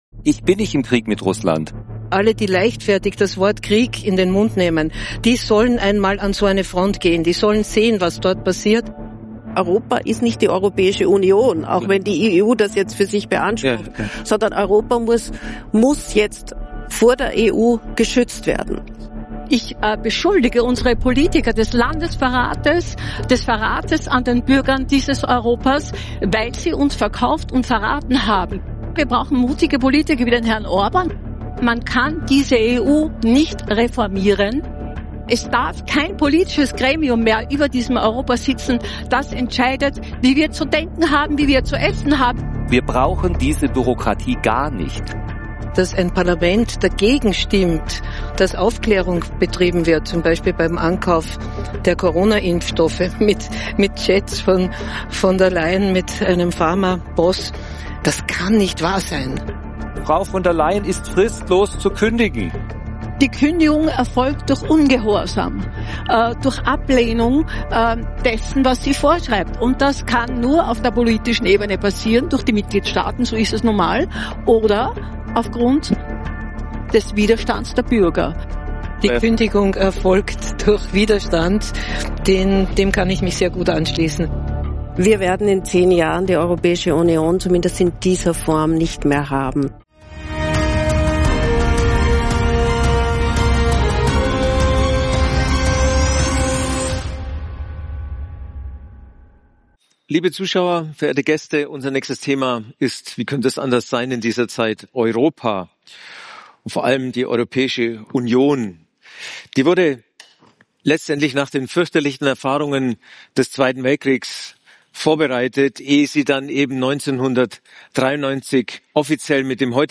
Was Elon Musk so sieht, das sehen auch Teilnehmer dieses spannenden EU-kritische Panels so.